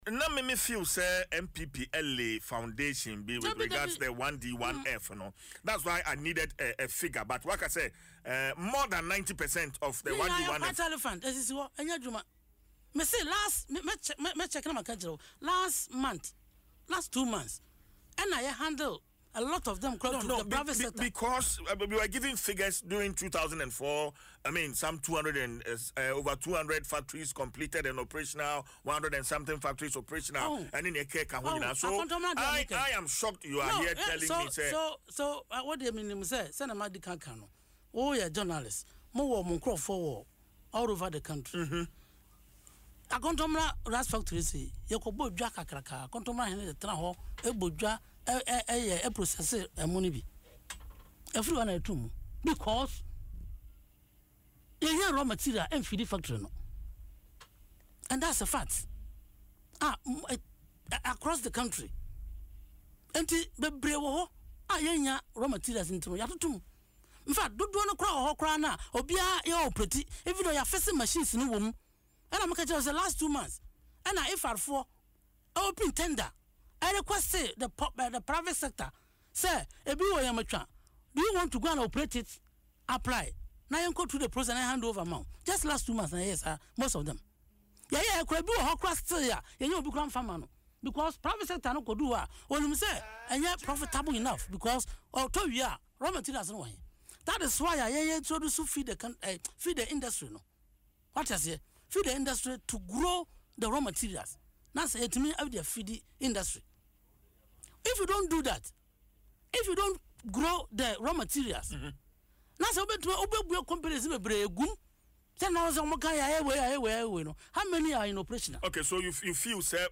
Speaking in an interview on Adom FM’s Dwaso Nsem, the Member of Parliament for Sehwi Bodi said many of the factories launched under the programme are now non-functional.